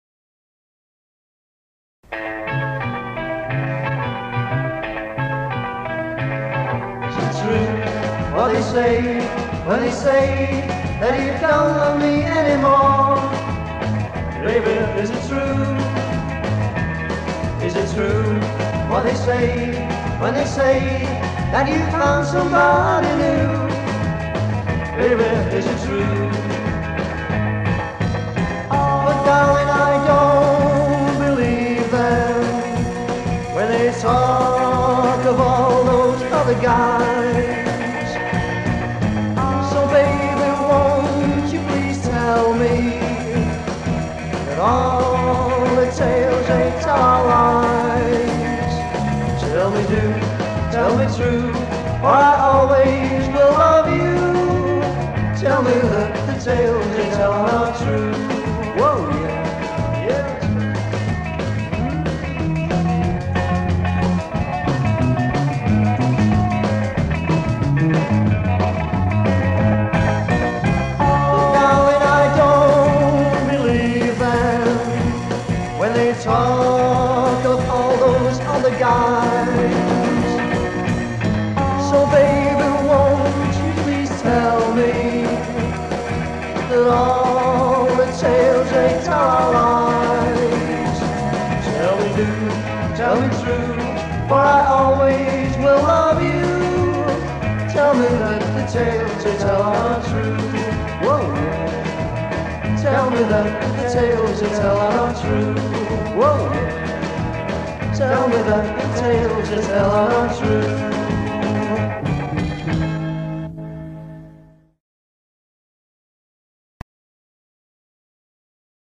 vocals
bass
drums
lead